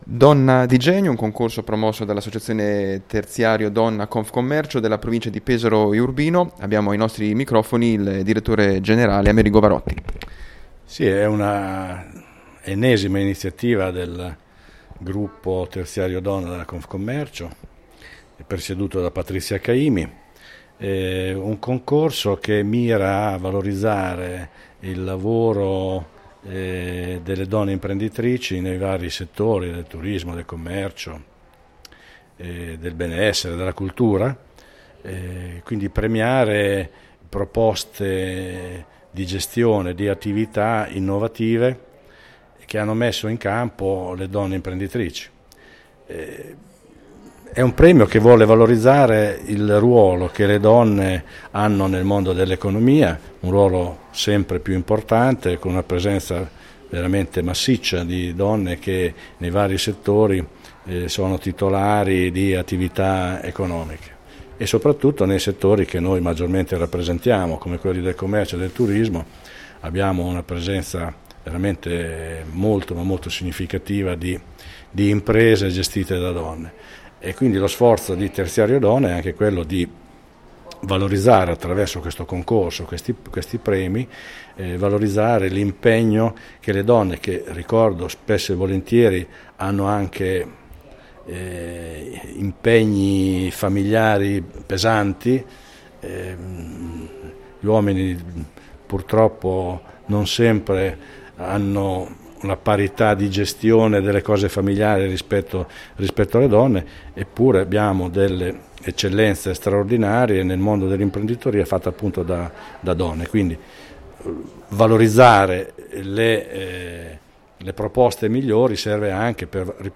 “Donna Di Genio” è un concorso promosso dall’Associazione Terziario Donna Confcommercio, per valorizzare idee e imprese caratterizzate dalla particolare sensibilità tipicamente femminile e capaci di rispondere alle nuove sfide di mercato, con particolare riferimento alla donna che lavora. Le nostre interviste a